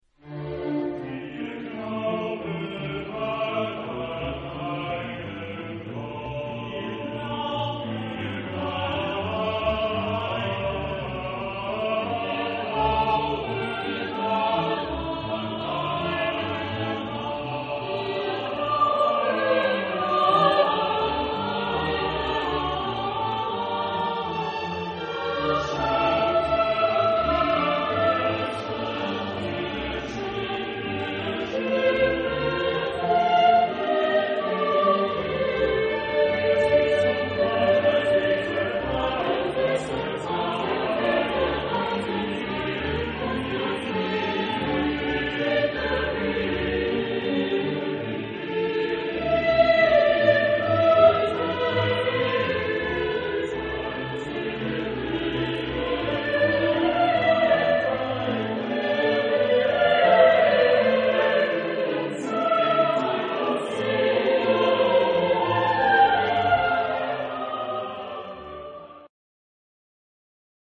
Genre-Style-Form: Sacred ; Romantic ; Cantata ; Chorale
Type of Choir: SATB  (4 mixed voices )
Instrumentation: Orchestra